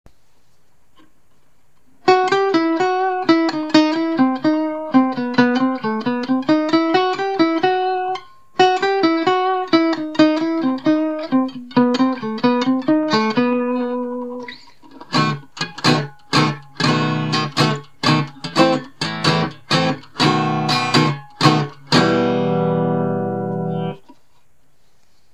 So I finally put Elixier 0.11 on and loved what it sounded like. Recorded the intro and the first chords of "Jovano, Jovanke" with my cheap mobile phone.
But it plays o.k., and the sound is quite unique.